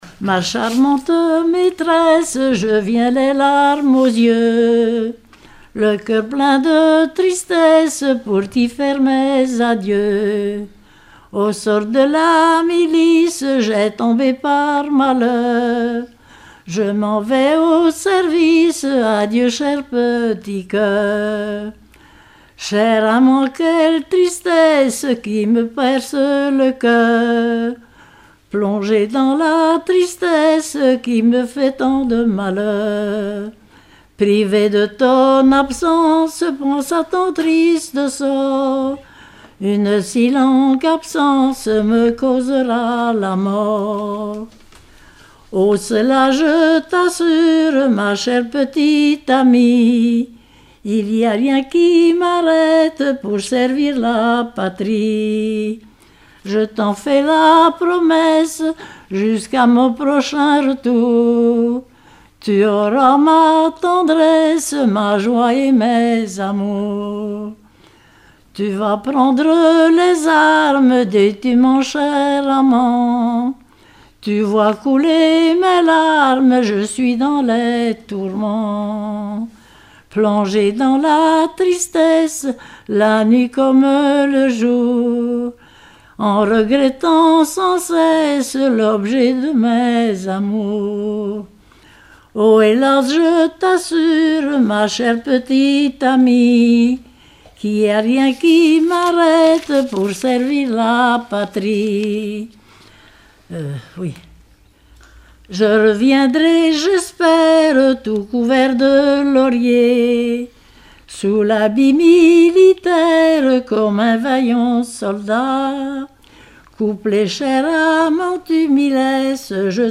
Genre strophique
enregistrement d'un collectif lors d'un regroupement cantonal
Pièce musicale inédite